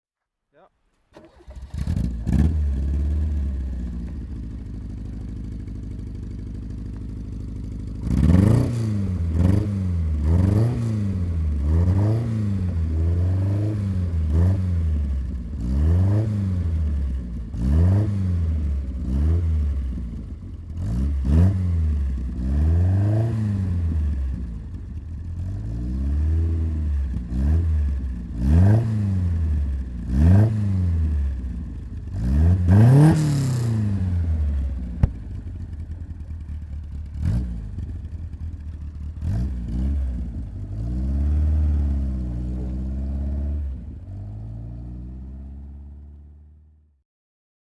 Lotus Super Seven S3 TwinCam (1969) - Starten und Leerlauf